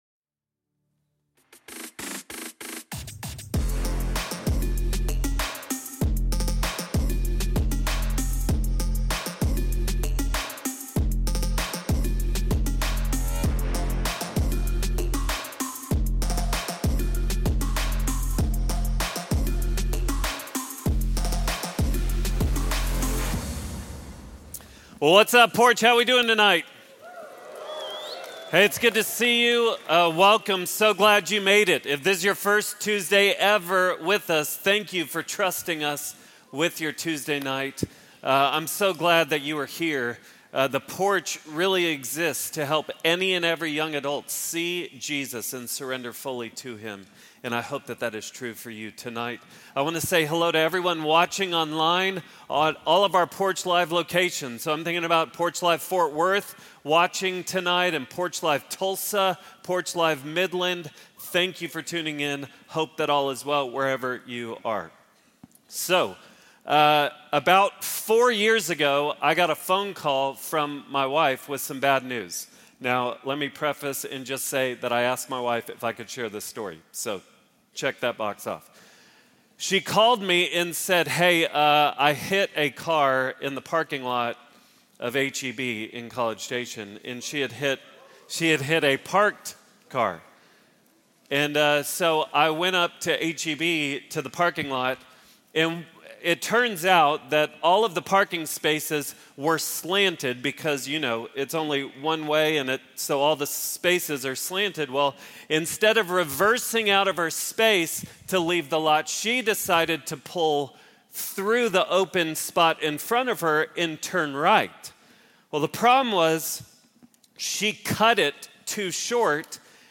Religion & Spirituality, Christianity